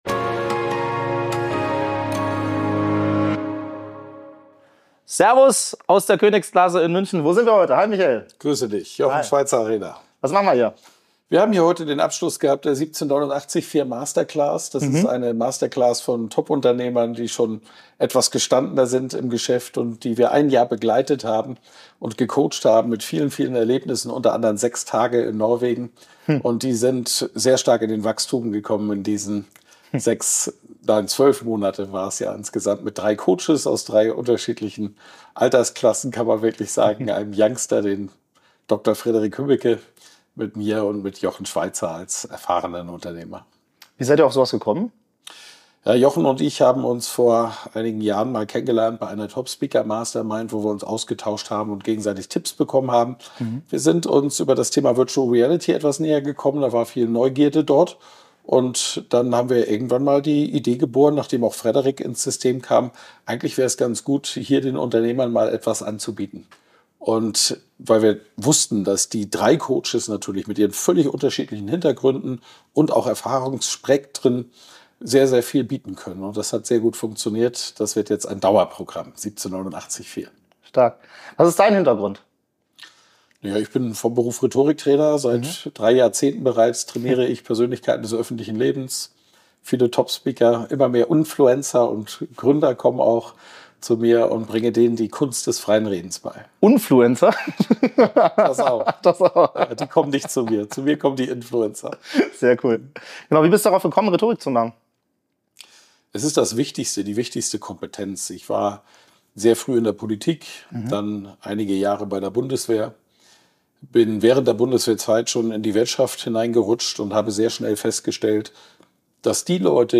Zusammenfassung Dieses Gespräch findet im Rahmen des Abschlusses der 1789vier Executive Retreat Masterclass statt, einer einjährigen Coaching-Reihe für erfahrene Unternehmer.